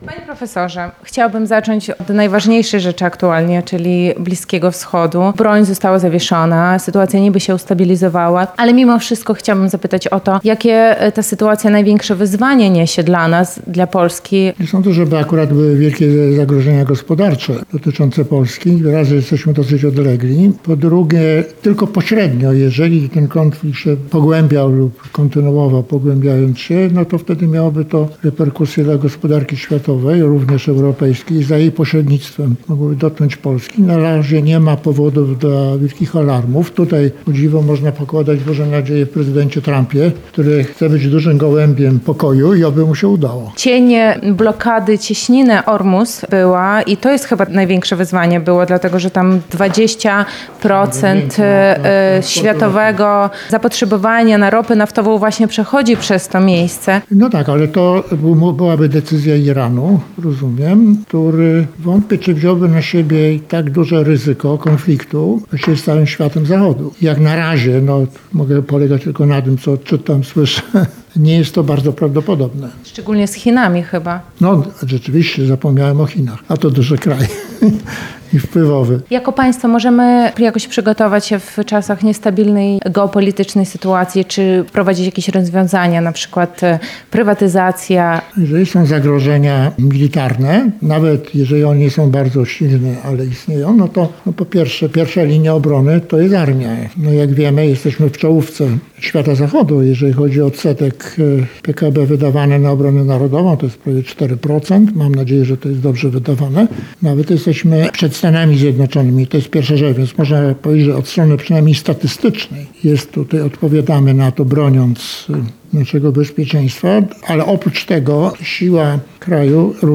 Rozmowa z prof. Leszkiem Balcerowiczem
O wyzwaniach gospodarczych w czasie geopolitycznych zmian oraz sposobach na zmniejszenie deficytu budżetowego z prof. Leszkiem Balcerowiczem rozmawiała nasza reporterka.